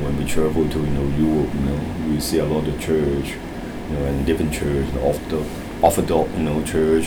S1 = Taiwanese female S2 = Hong Kong male Context: S2 is talking about his exposure to different religions. S2 : ... we travel to you know europe you know we see a lot of church (.) er different church the orthodo- orthodox you know church Intended Words : different church Heard as : deventray Discussion : One problem is the absence of [r] in different , which is pronounced as [dɪfən] .